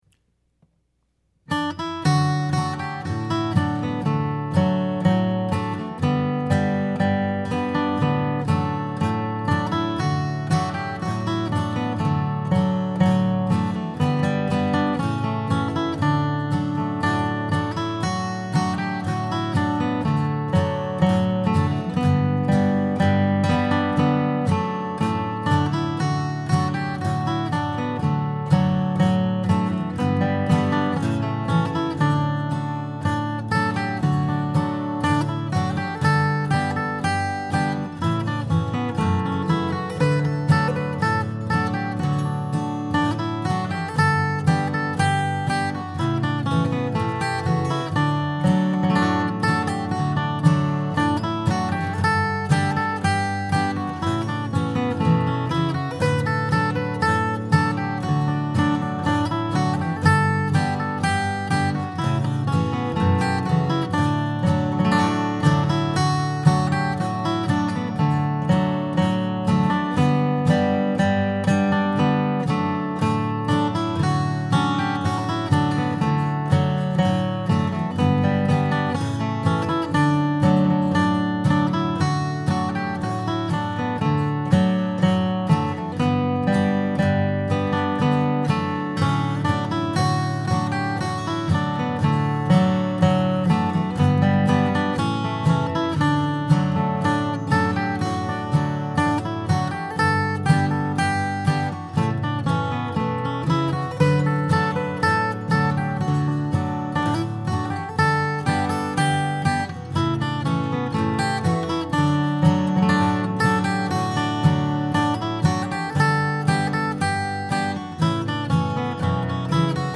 ST. ANNE'S REEL | GUITAR
St. Anne's Reel Key of D (60 bpm) (.mp3 file)
StAnnesReel60_guitar.mp3